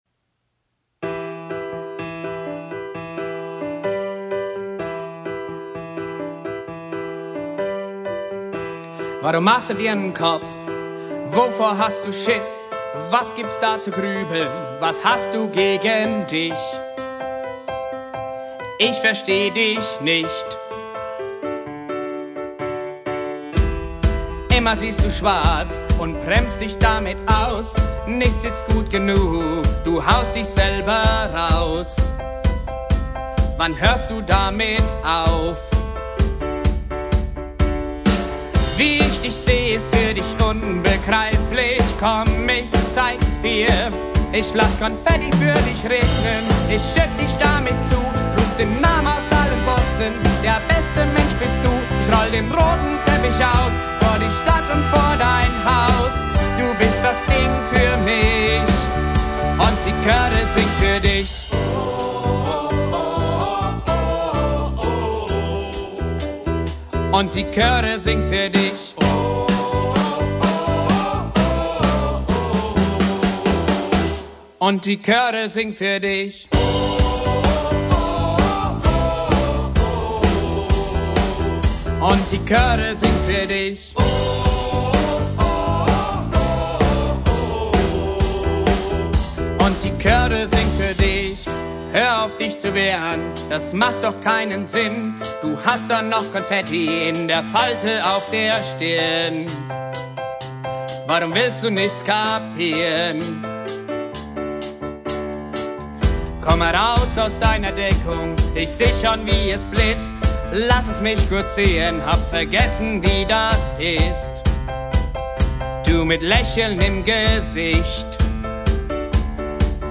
Live - Mitschnitte (Auszüge)
Chöre